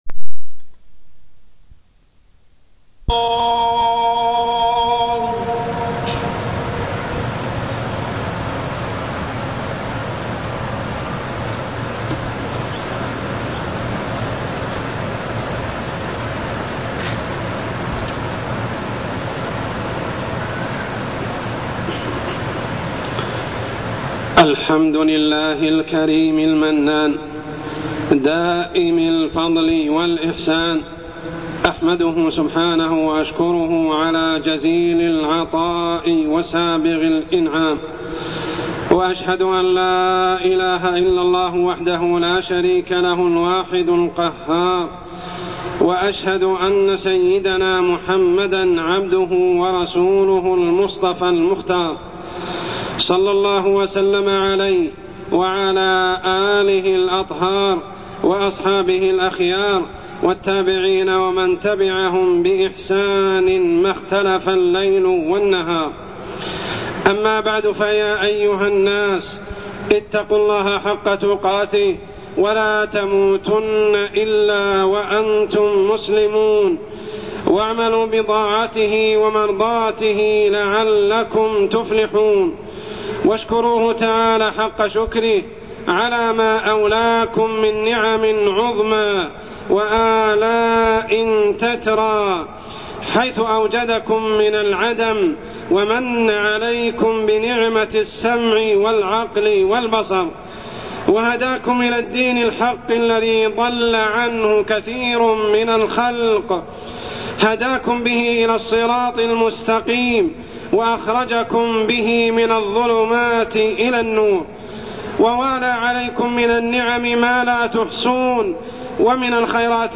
تاريخ النشر ١٤ ربيع الأول ١٤٢١ هـ المكان: المسجد الحرام الشيخ: عمر السبيل عمر السبيل شكر النعم